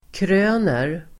Uttal: [kr'ö:ner]